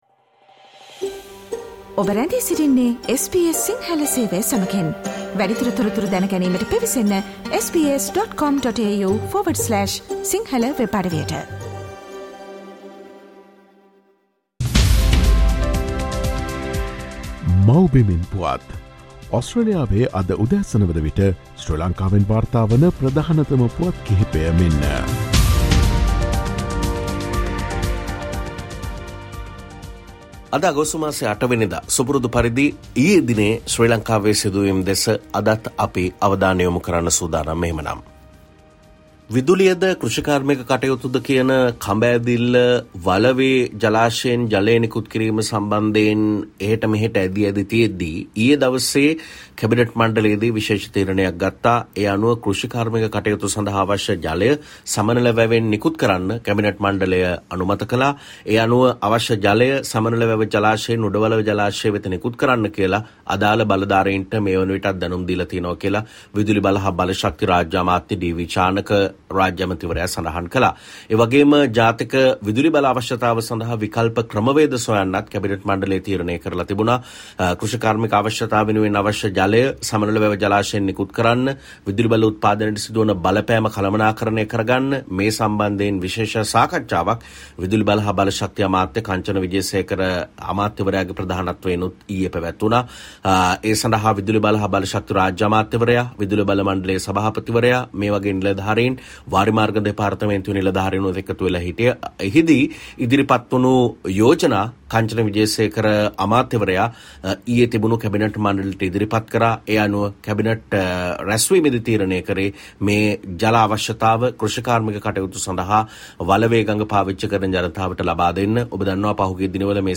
SL News report 08 August: 2 young officers killed in Sri Lanka after air force plane crashes